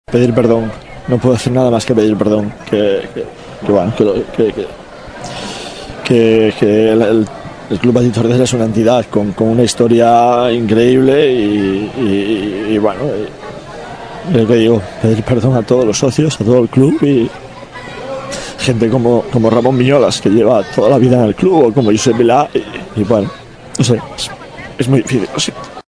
no podia aguantar les llàgrimes després de la desfeta torderenca i demanava disculpes pel que havia succeït.